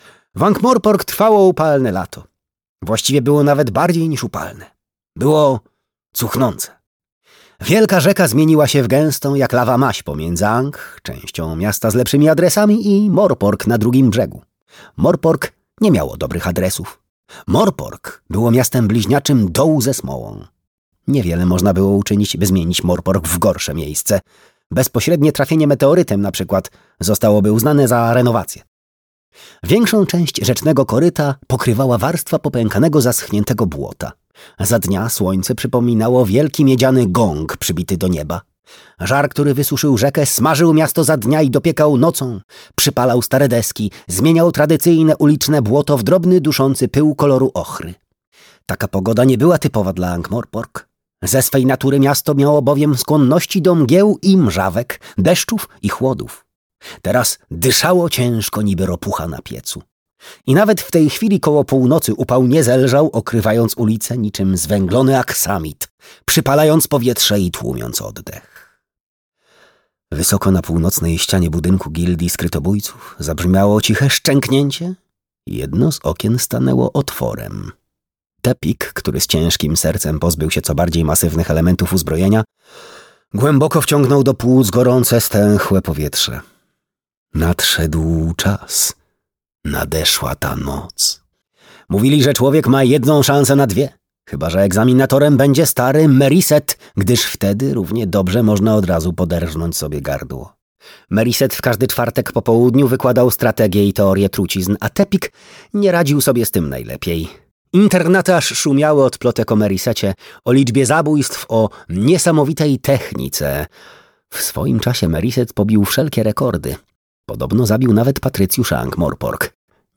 Piramidy - Terry Pratchett - audiobook